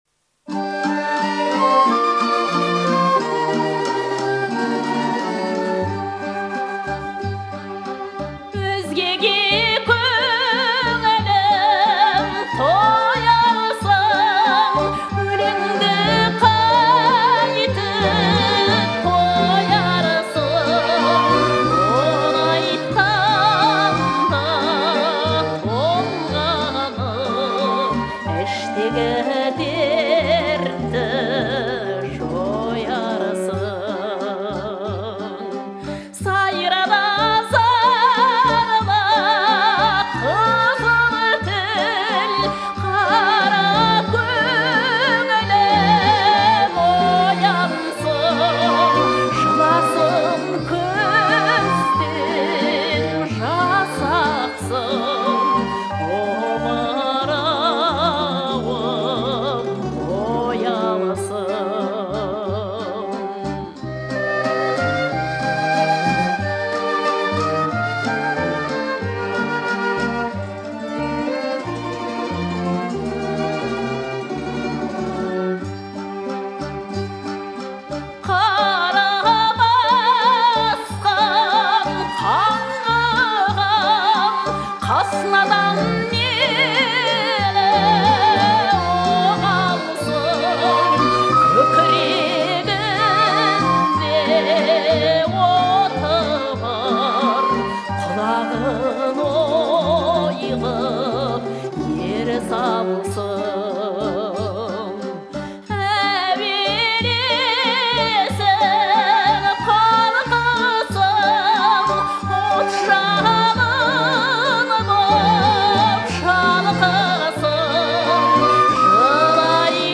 Жанр Эстрадалық әндер